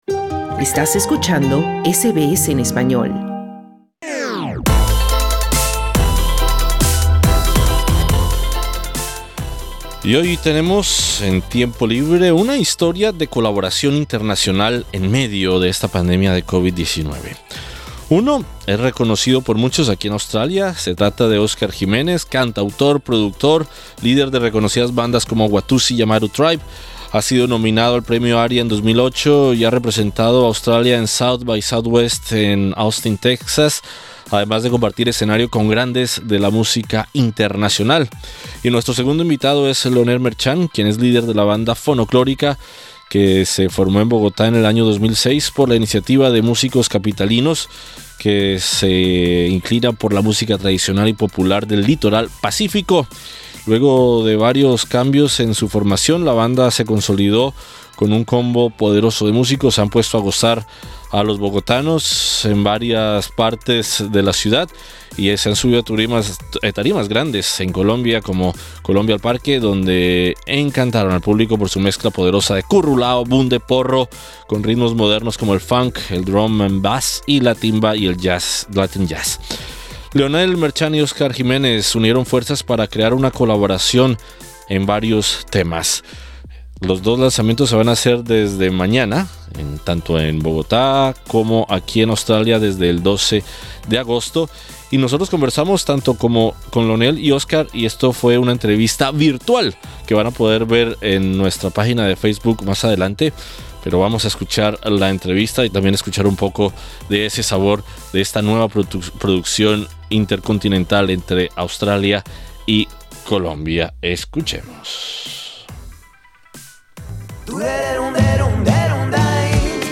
Escucha la entrevista en SBS Spanish.